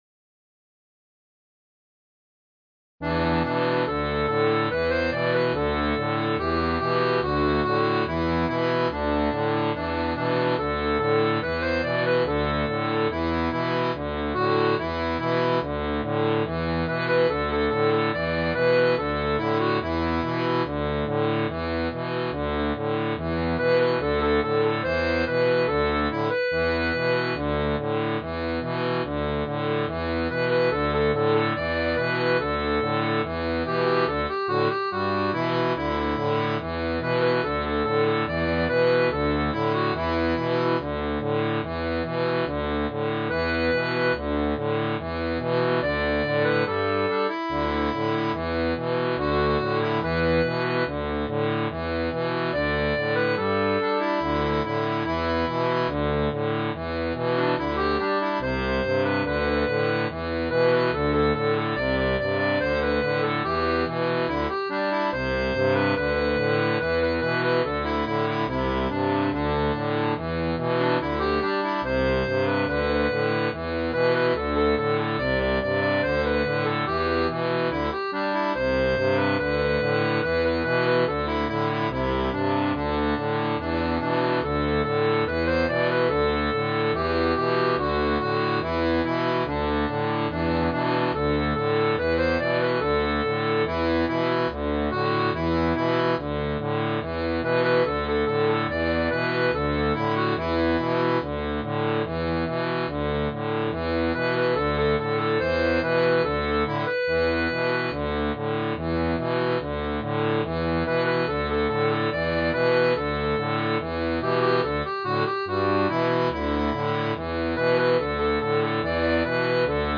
• Une tablature pour diato 2 rangs transposée en Mi
Pop-Rock